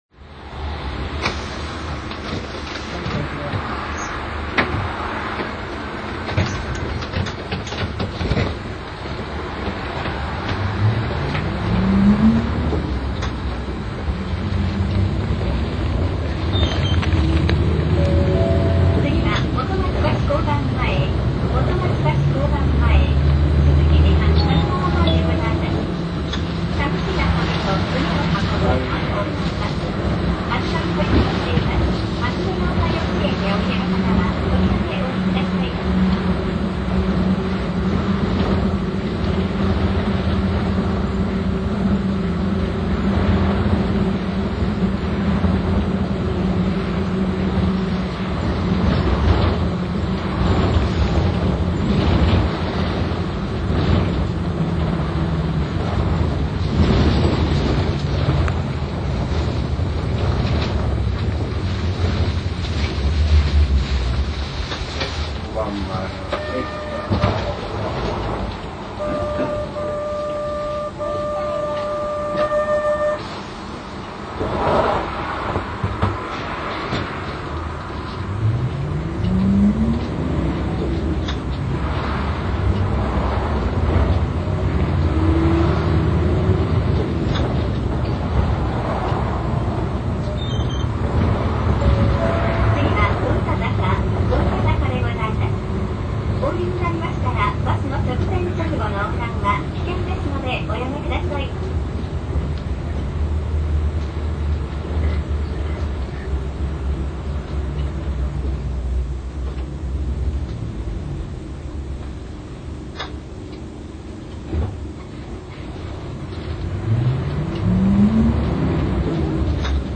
Ｐ−代との違いは４速低回転からの立ち上がりのエンジン音で、高音成分が少なくなっている
横４６系統　樹源寺前〜児童遊園地入口